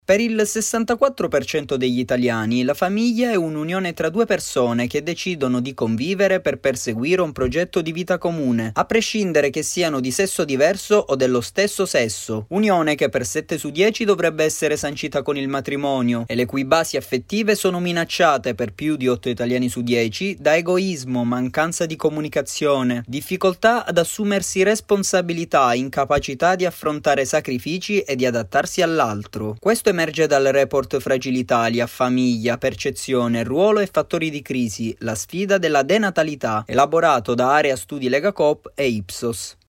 Servizio-Grs-17-maggio.mp3